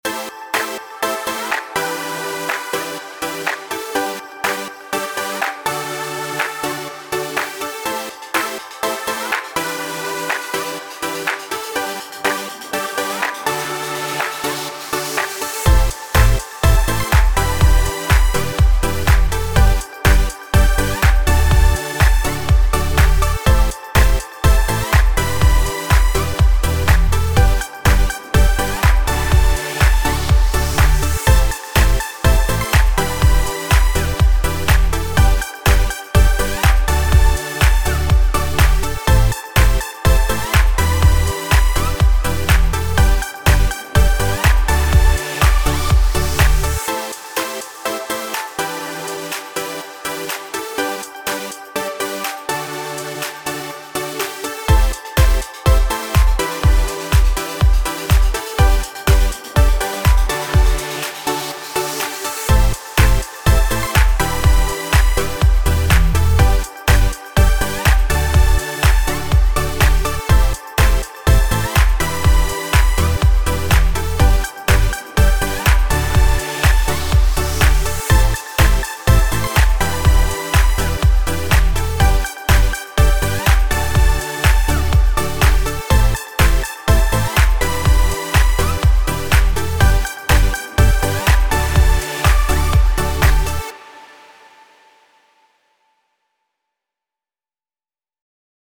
Music that empowers and elevates your daily workout spirit